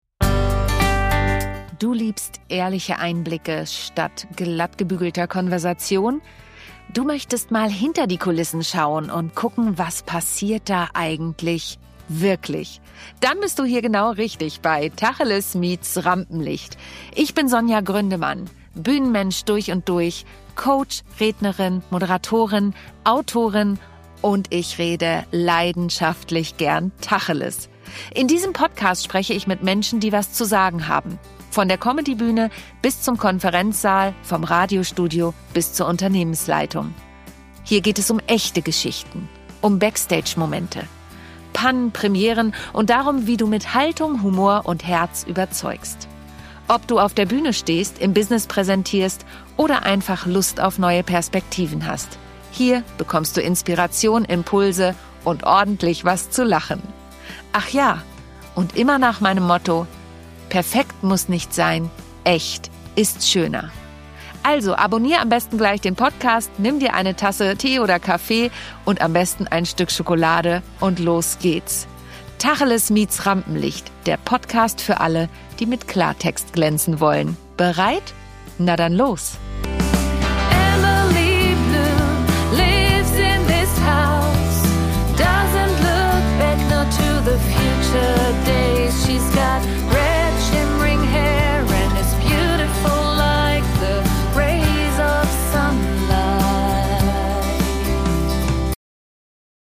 Du hörst ihn im Intro und Outro und kannst ihn auf allen Plattformen streamen.